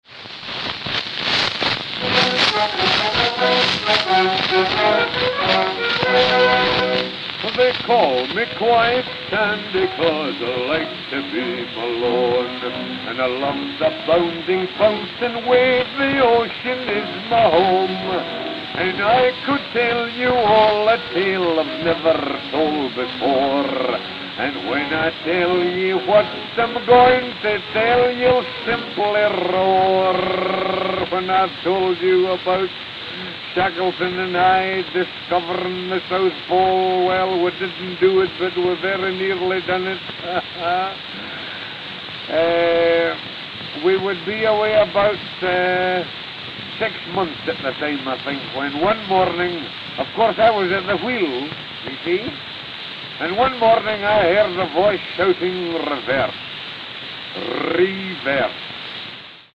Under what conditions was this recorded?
Notes: played at 85 RPM; listen at 80 RPM; also worn in the middle